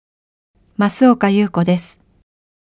ボイスサンプルはこちら↓